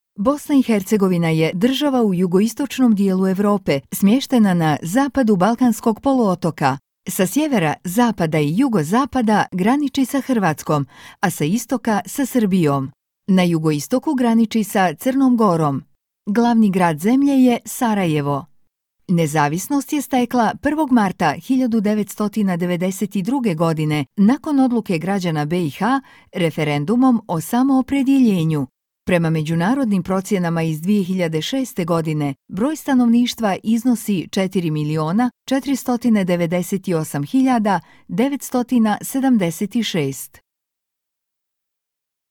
Boşnakca Seslendirme
Kadın Ses